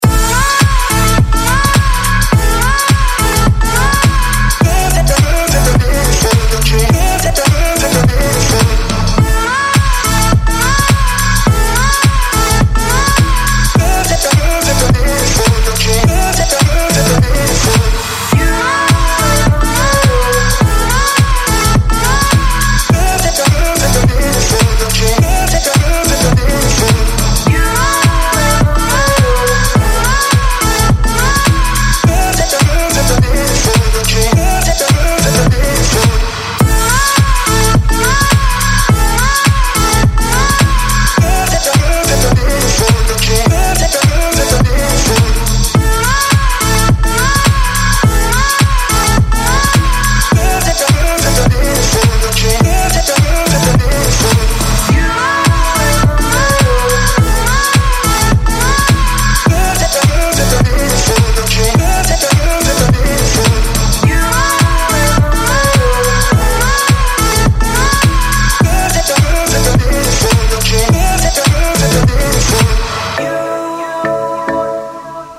• Качество: 128, Stereo
без слов